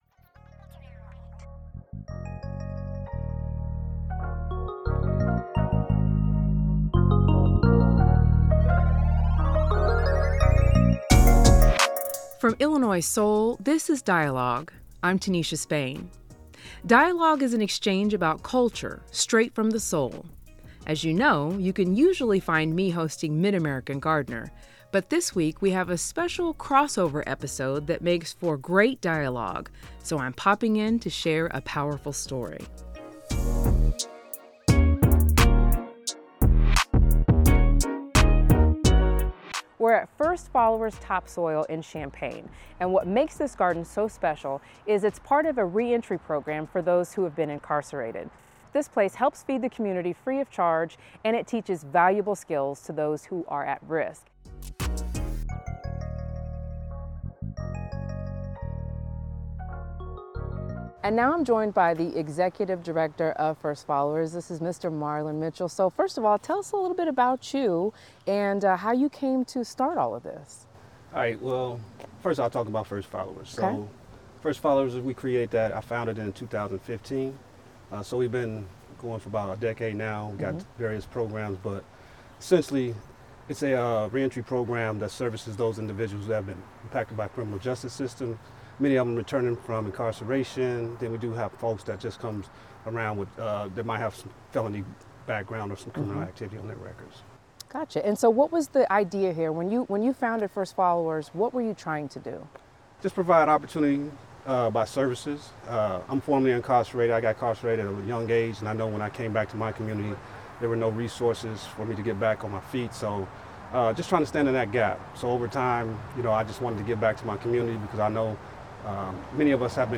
This conversation explores how growing plants becomes a path to healing, rebuilding and community connection beyond prison walls.